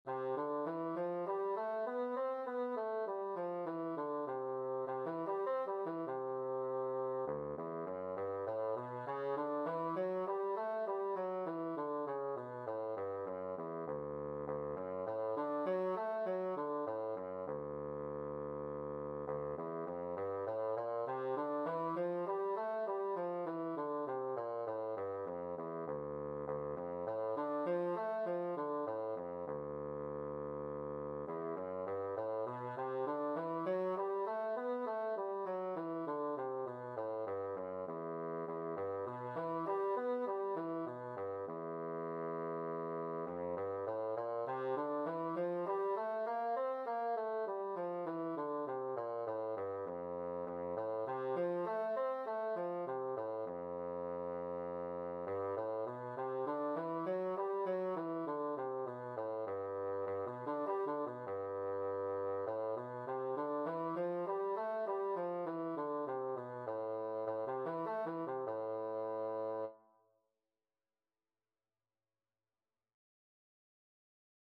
Bassoon scales and arpeggios - Grade 2
C major (Sounding Pitch) (View more C major Music for Bassoon )
D3-C5
4/4 (View more 4/4 Music)
bassoon_scales_grade2.mp3